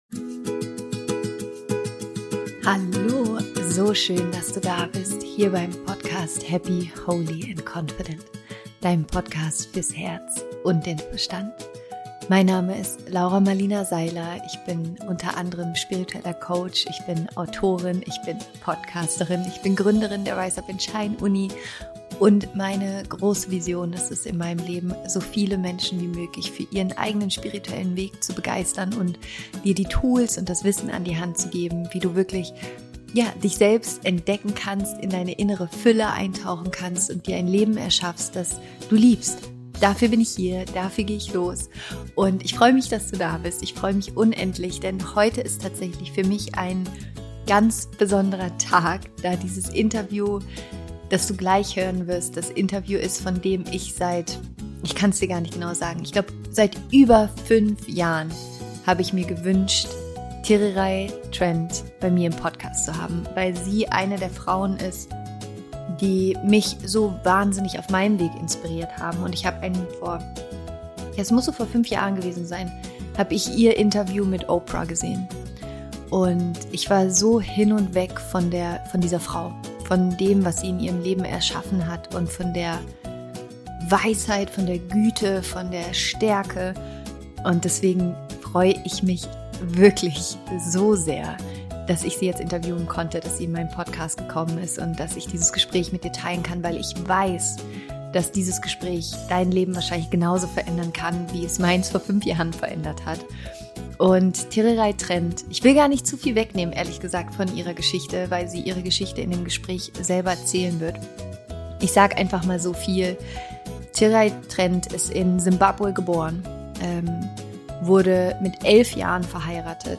Wie du dein Familienschicksal veränderst - Interview Special mit Tererai Trent